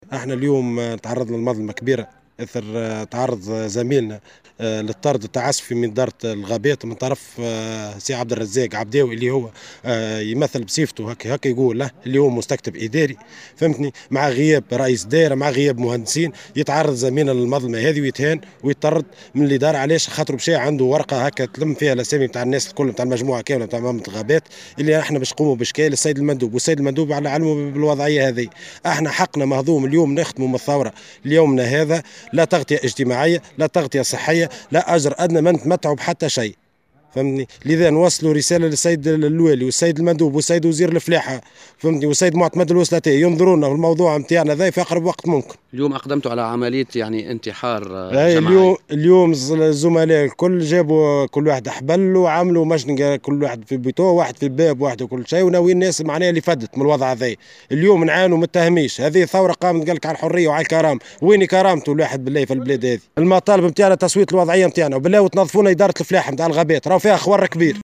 وأوضح احد المحتجين في تصريح لمراسل الجوهرة اف ام، أن احتجاجهم اليوم يأتي على خلفية تعرّض زميلهم للطرد التعسفي من طرف إدارة الغابات، إلى جانب مطالبتهم بتسوية وضعيتهم المهنية المهمشة.